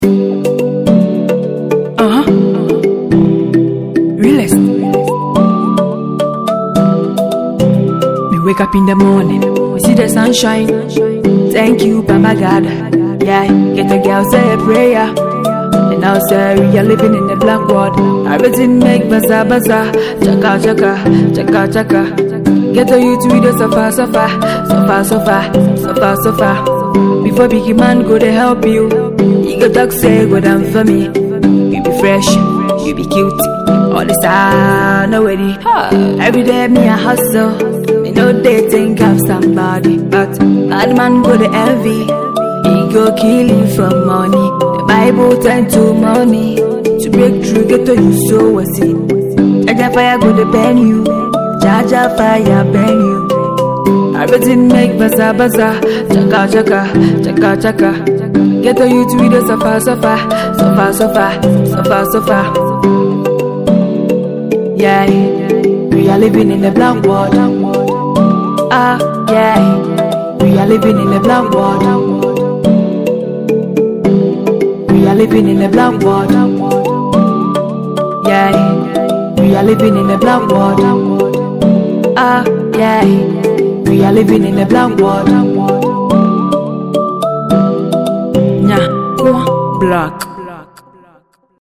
Ghanaian female afro musician